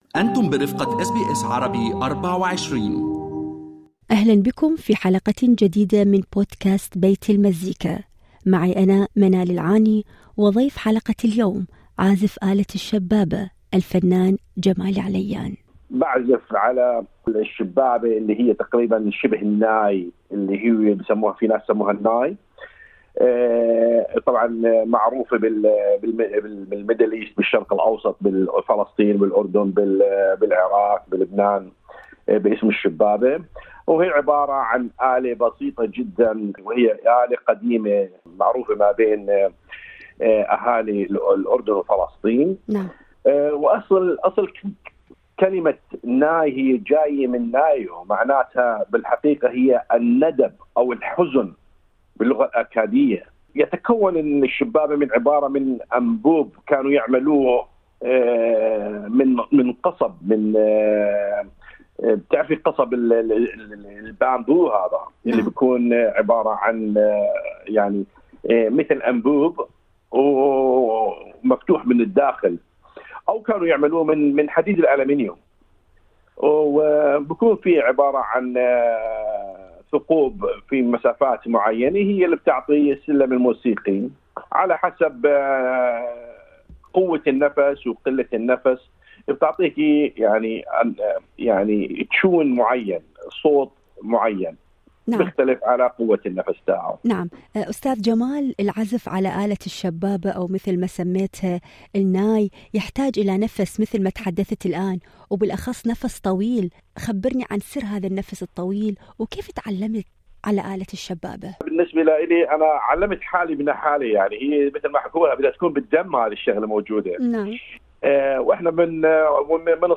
Bayt Almazzika Podcast with Shababah instrument player artist